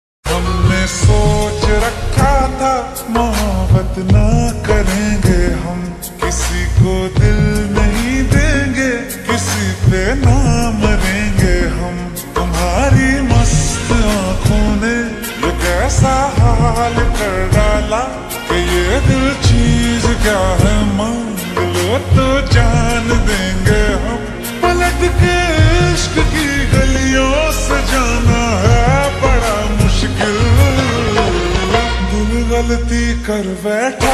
Slow Reverb Version
• Simple and Lofi sound
• High-quality audio
• Crisp and clear sound